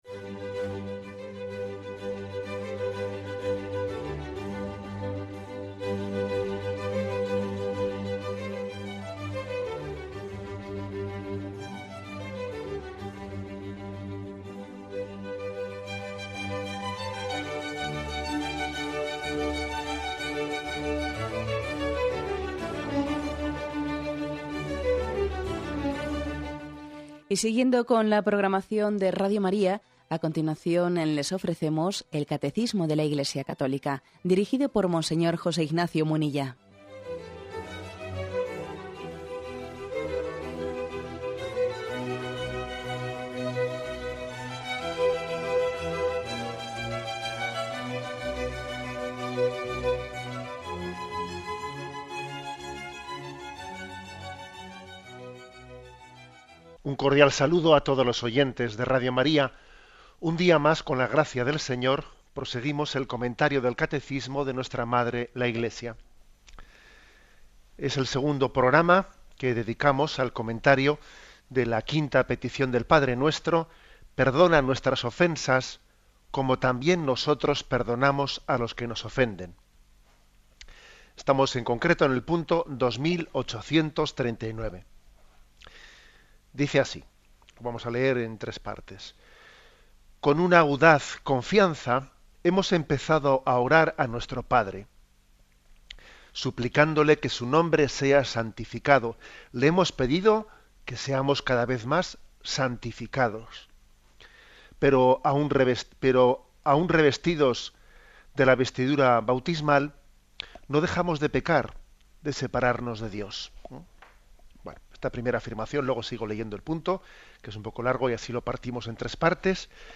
Gènere radiofònic Religió Presentador/a Munilla, José Ignacio